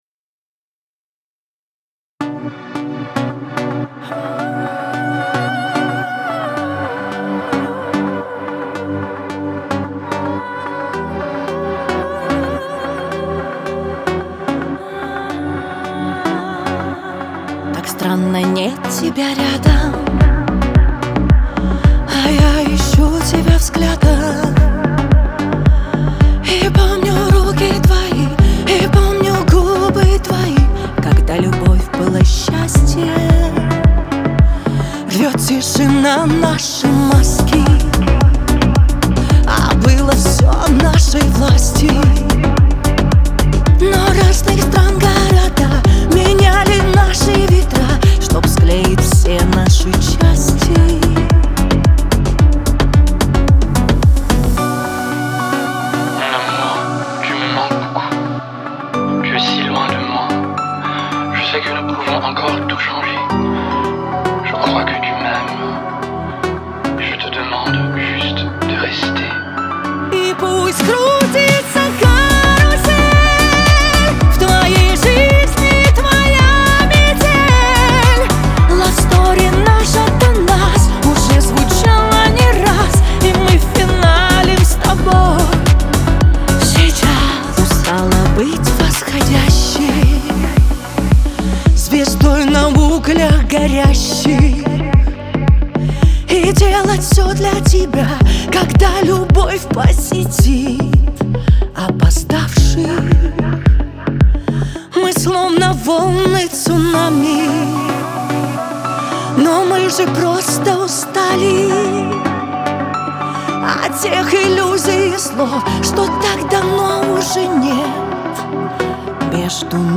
диско , pop
эстрада